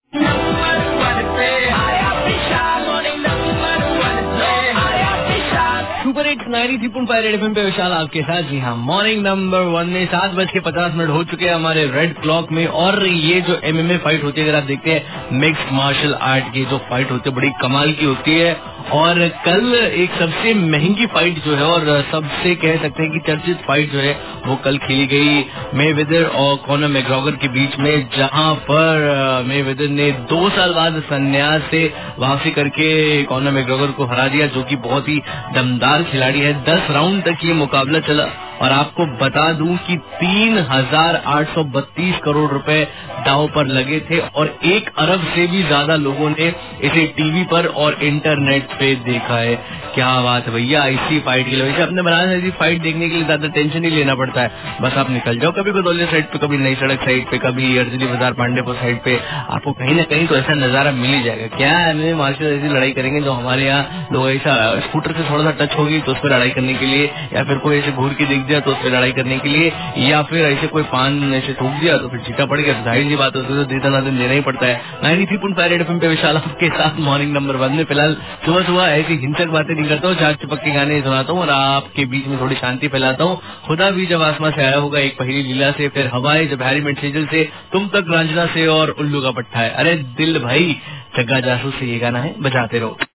Rj About MMA Fight